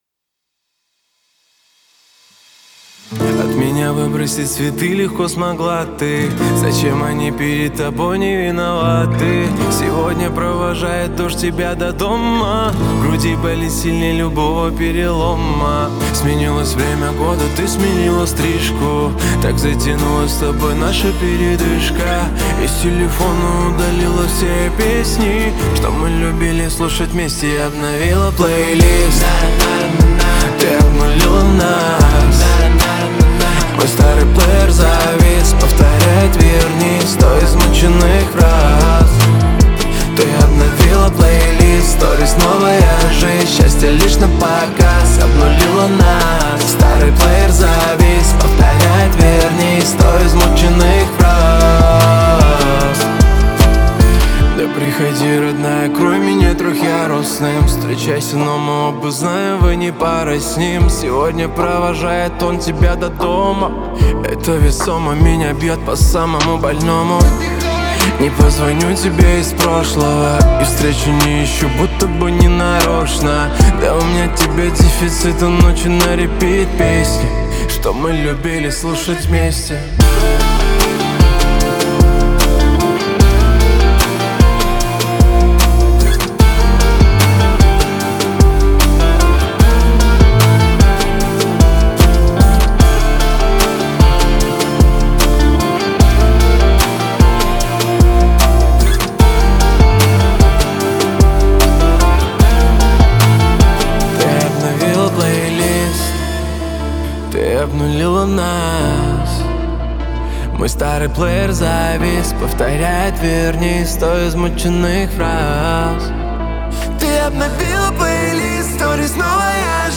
энергичная поп-музыка